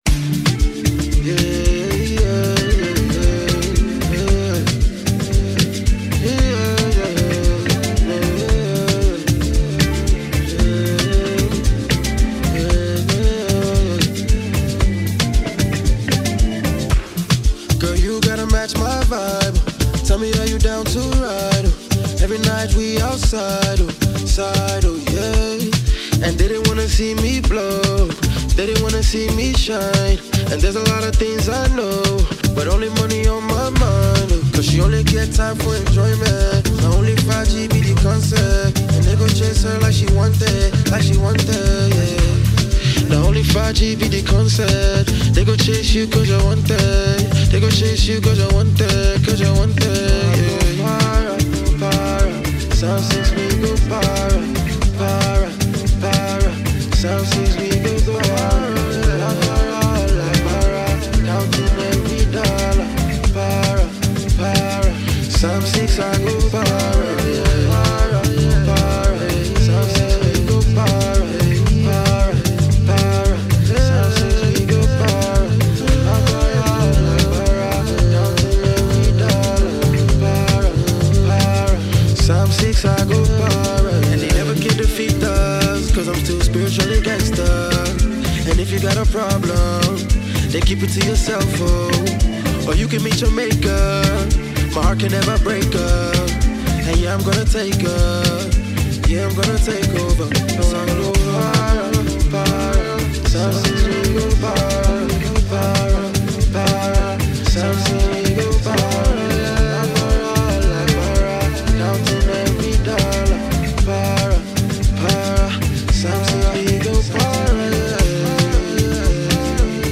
moody, meticulously crafted single